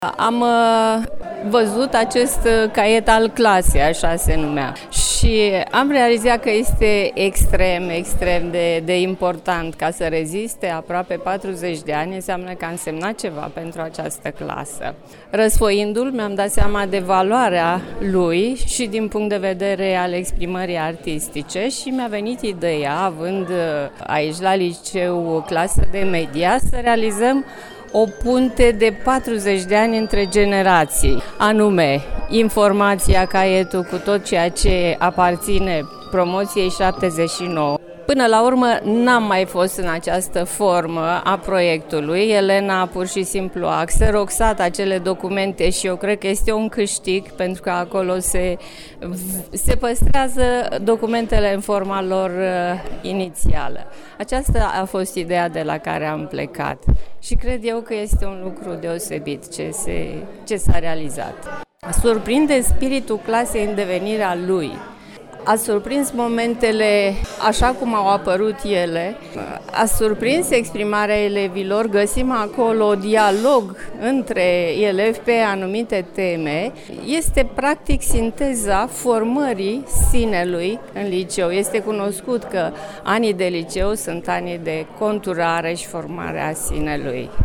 VIDEO și GALERIE FOTO: Sinceritate și bucurie, la revederea de 40 de ani a promoției `79, a Liceului Tehnologic „Grigore C. Moisil”, din Buzău